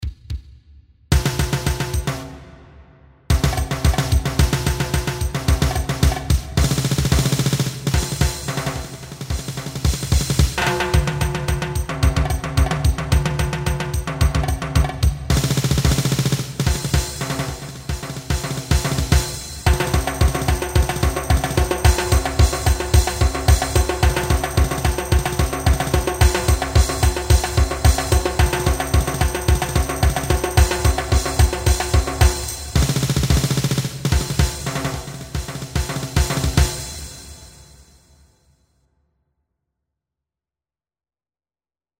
Ongestemd Marcherend Slagwerk
Snare Drum Bongo's Timbales Beatring Cymballs Bass drum